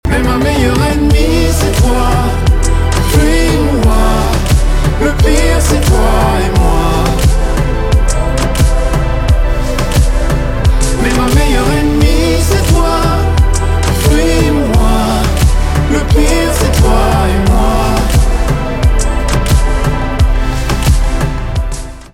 поп
красивые , чувственные
грустные , танцевальные